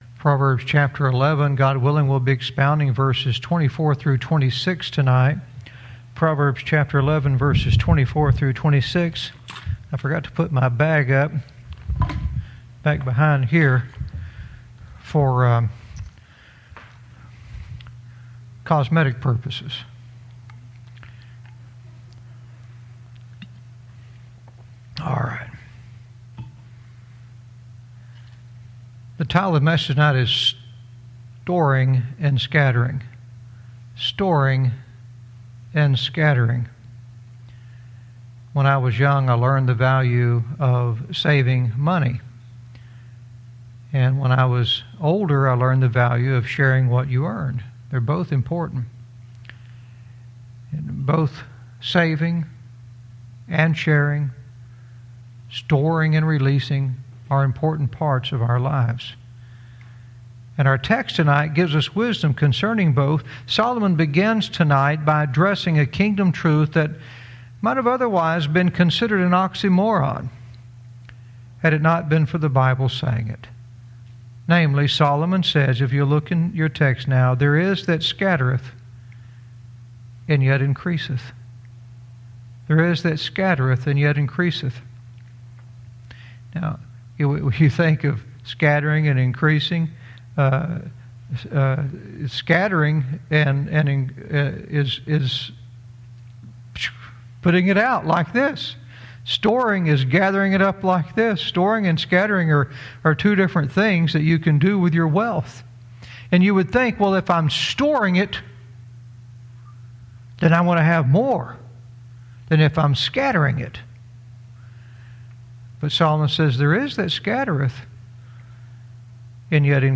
Verse by verse teaching - Proverbs 11:24-26 "Storing & Scattering"